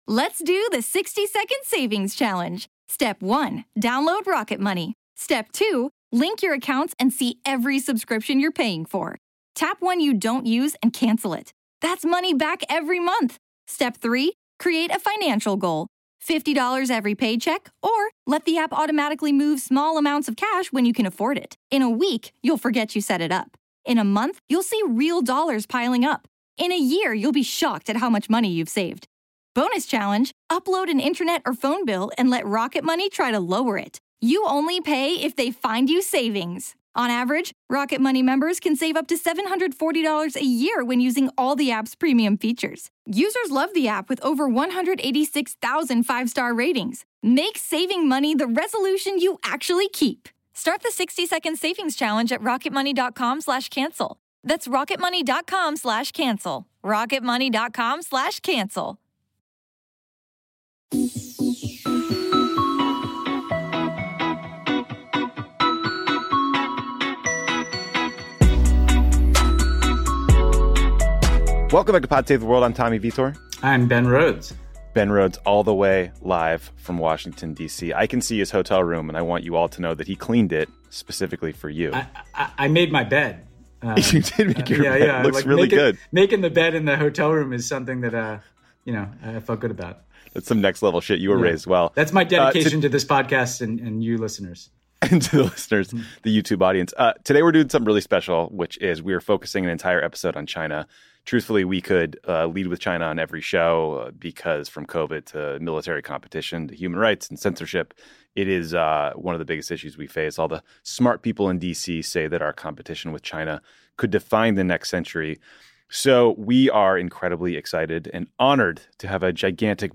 Tommy and Ben are joined by Asia policy expert Danny Russel for a special episode that is entirely focused on China. They discuss Chinese President Xi Jinping’s background and worldview, Wolf Warrior diplomacy, China’s human rights record and suppression of the Uyghurs, and the fear that war between the US and China is inevitable and could start over Taiwan.